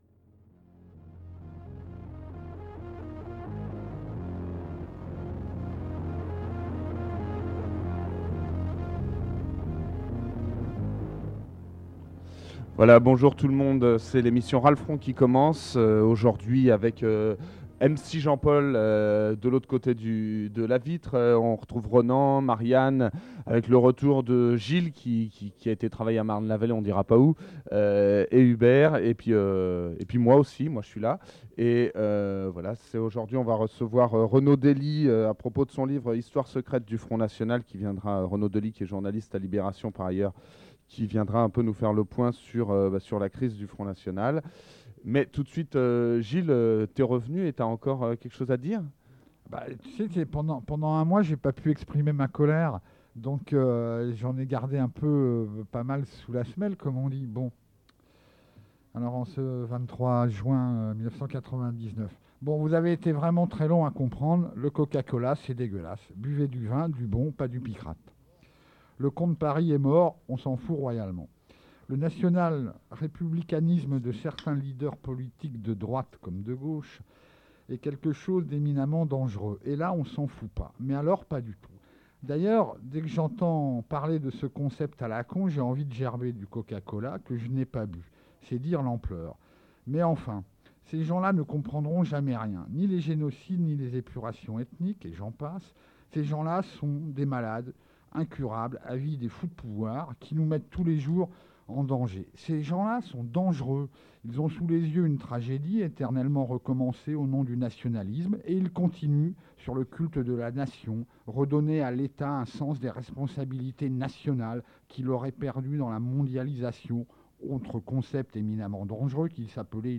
Emission en compagnie du journaliste Renaud Dély à l'occasion de la parution de son ouvrage Histoire secrète du Front national chez Grasset.